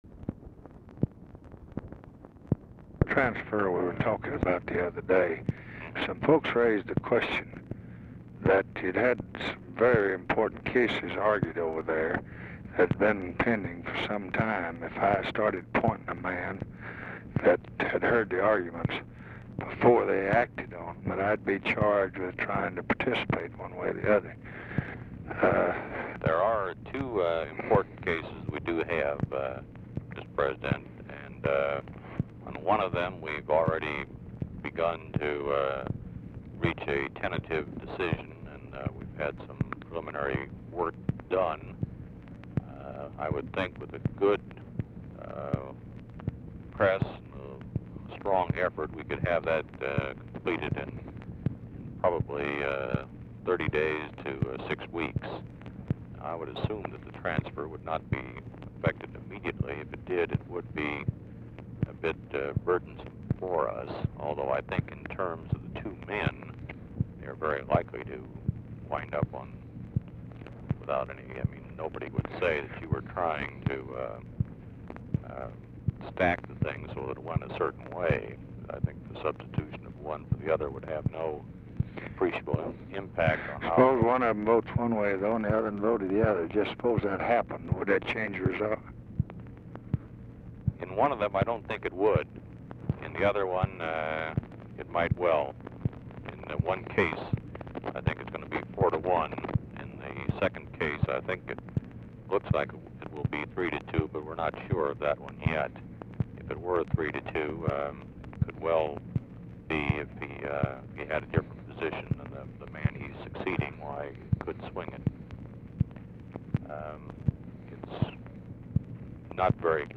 Telephone conversation # 10101, sound recording, LBJ and LEE WHITE, 5/2/1966, 3:34PM | Discover LBJ
RECORDING STARTS AFTER CONVERSATION HAS BEGUN AND ENDS BEFORE IT IS OVER
Format Dictation belt
Location Of Speaker 1 Mansion, White House, Washington, DC